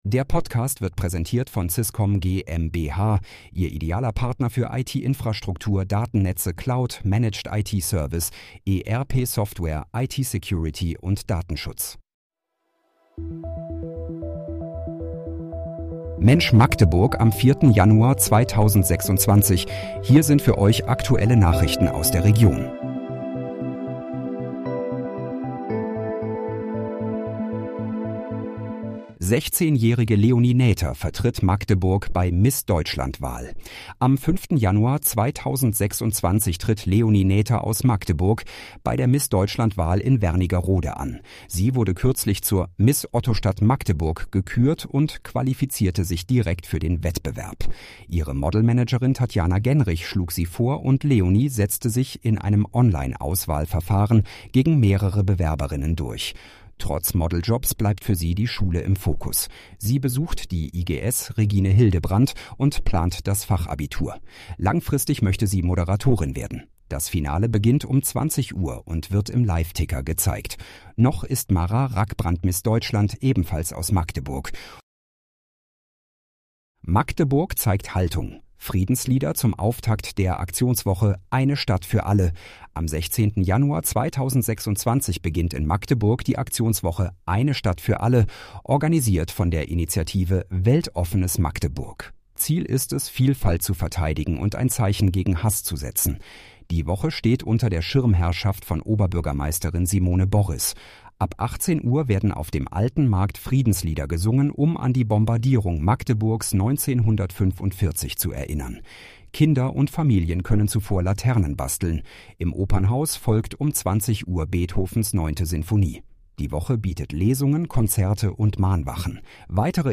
Mensch, Magdeburg: Aktuelle Nachrichten vom 04.01.2026, erstellt mit KI-Unterstützung